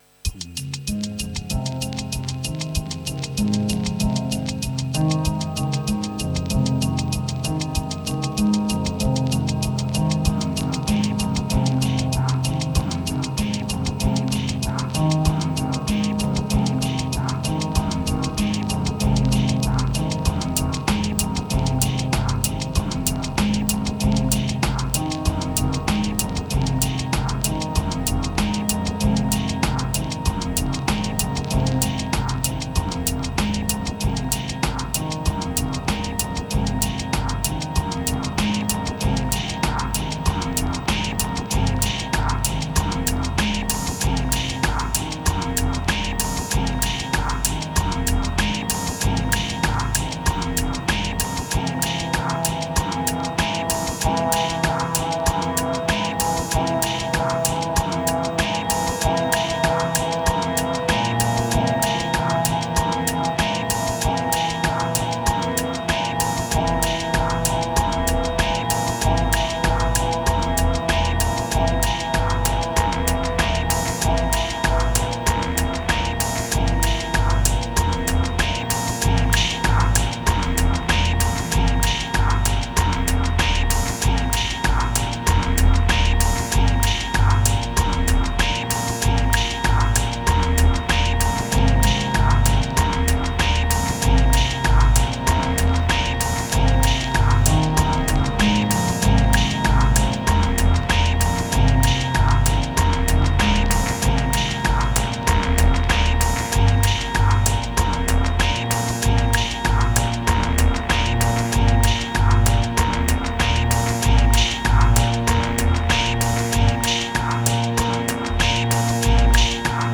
1164📈 - 86%🤔 - 96BPM🔊 - 2024-01-29📅 - 220🌟
Ambient Wax Ladder Moods Horizontal Deep Hopeless Static